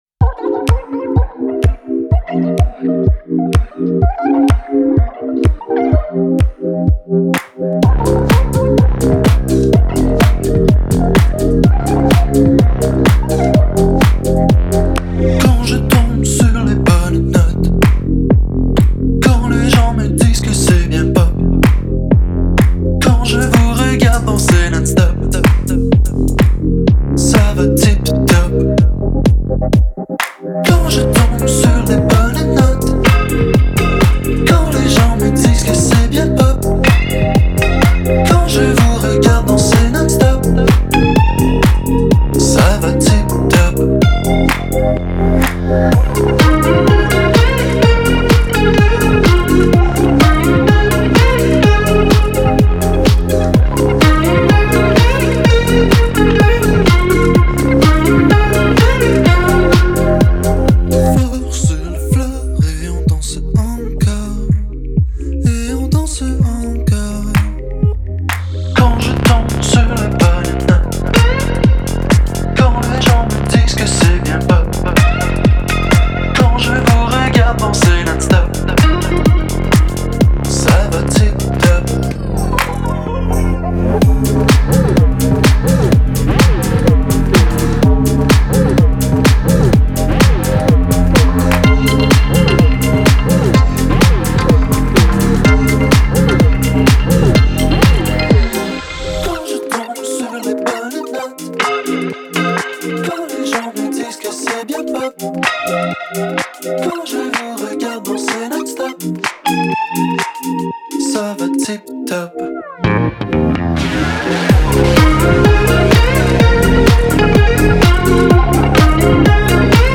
Un titre parfaitement léger et pop qui fait du bien.
un son envoutant flirtant avec des sonorités nu-disco